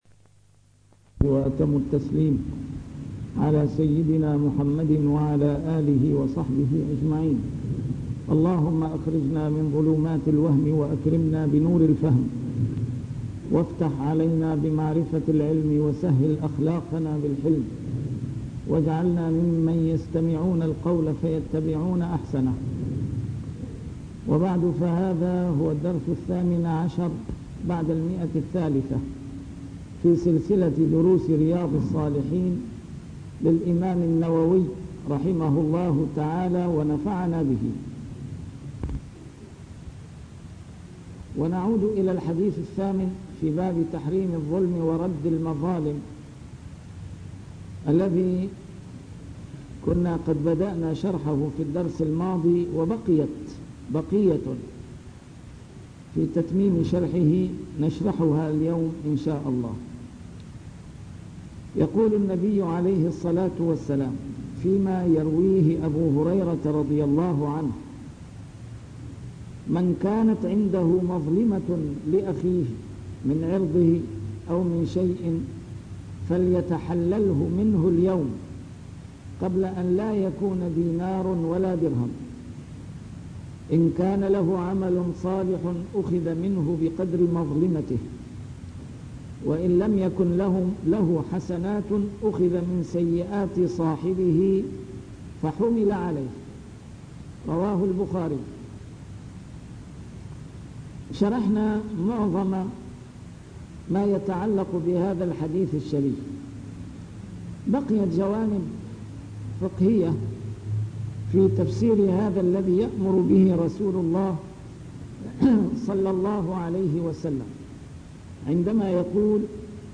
A MARTYR SCHOLAR: IMAM MUHAMMAD SAEED RAMADAN AL-BOUTI - الدروس العلمية - شرح كتاب رياض الصالحين - 318- شرح رياض الصالحين: تحريم الظلم